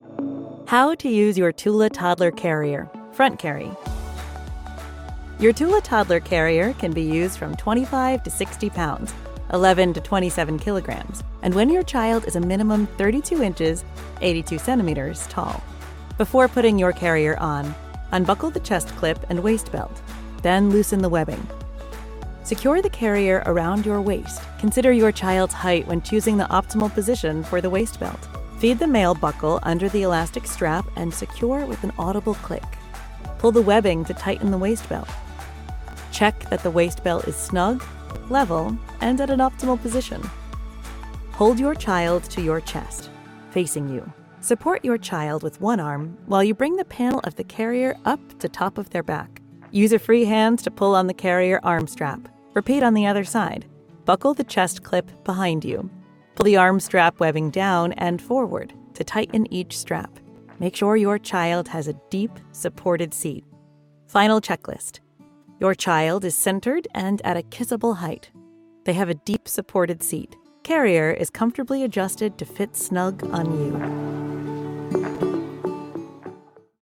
Natürlich, Zugänglich, Erwachsene, Freundlich, Warm
E-learning
▸ Her voice is natural, real, and emotionally present.